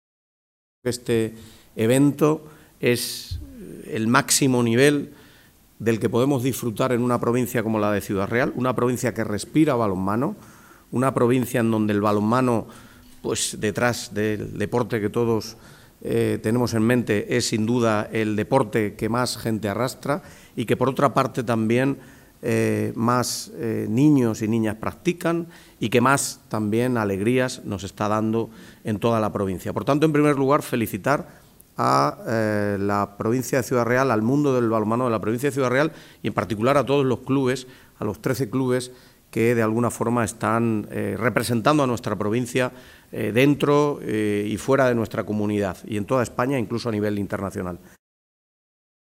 presentacion_partido_balonmano_espana-serbia_corte_pte_02.mp3